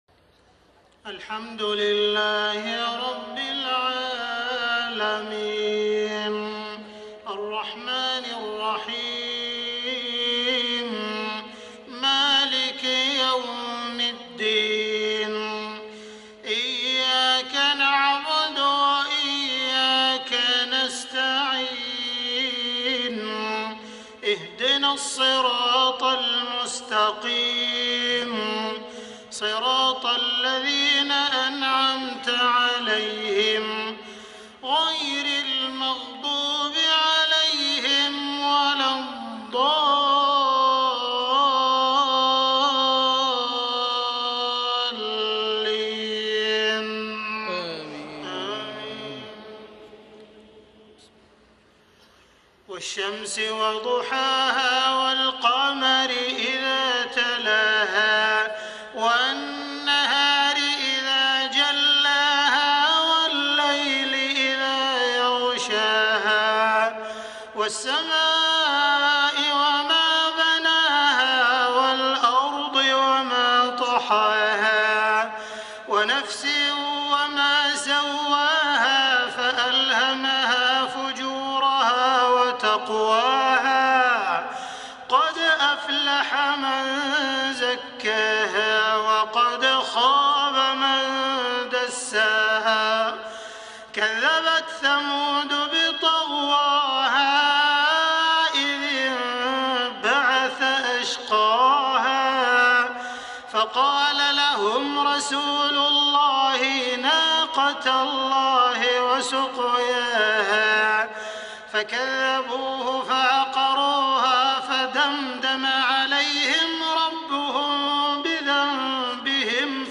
صلاة المغرب ١٤ محرم ١٤٣٤هـ سورتي الشمس و القارعة | > 1434 🕋 > الفروض - تلاوات الحرمين